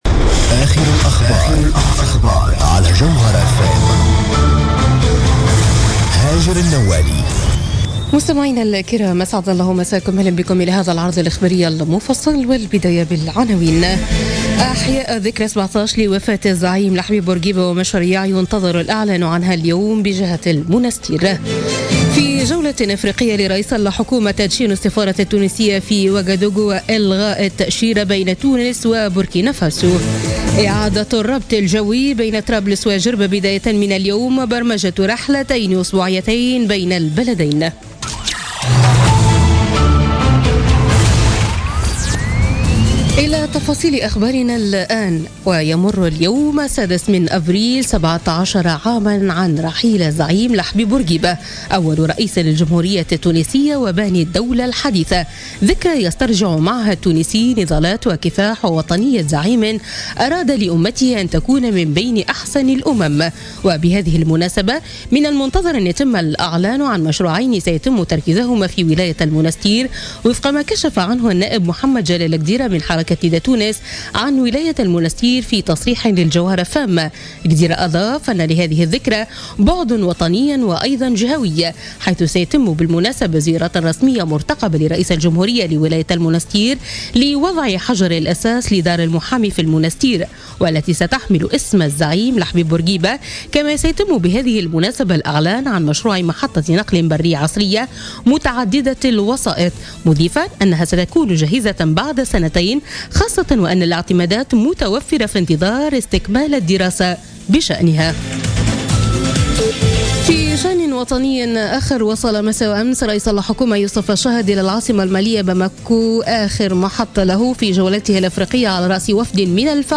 نشرة أخبار منتصف الليل ليوم الخميس 6 أفريل 2017